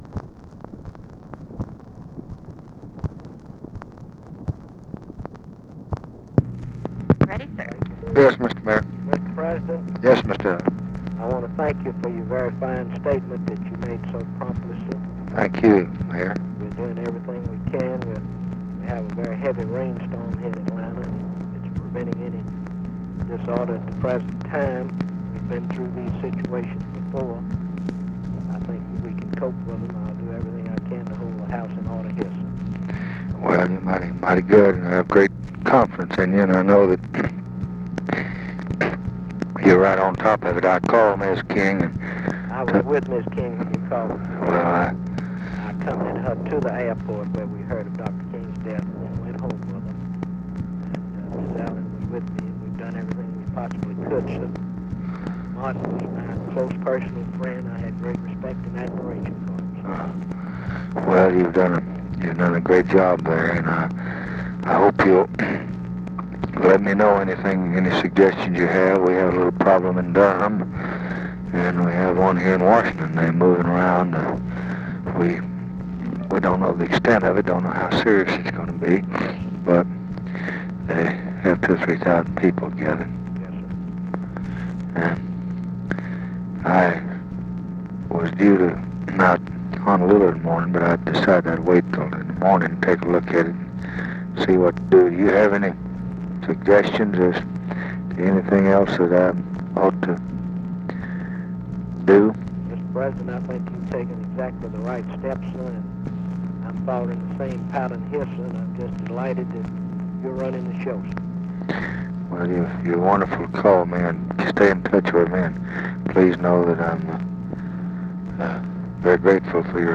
Conversation with IVAN ALLEN and LADY BIRD JOHNSON, April 5, 1968
Secret White House Tapes